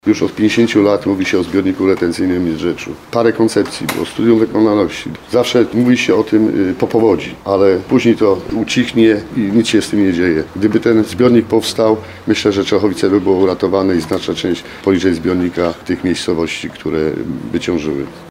Myślę, że gdyby zbiornik istniał, Czechowice byłyby uratowane i znaczna część miejscowości poniżej niego – to słowa wicewójta gminy Krzysztofa Wieczerzaka, które padły podczas konferencji prasowej w Starostwie Powiatowym w Bielsku-Białej tuż po powodzi.